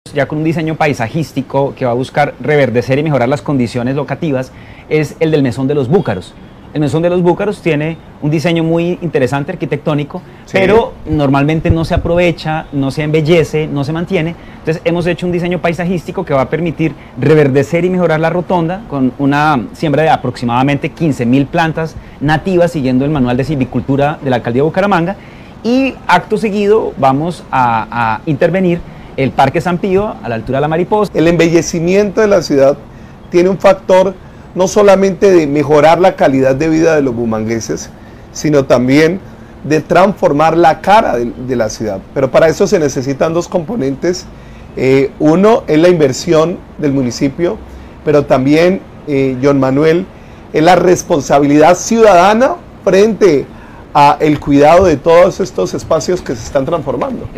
John Manuel Delgado, Director Área Metropolitana de Bucaramanga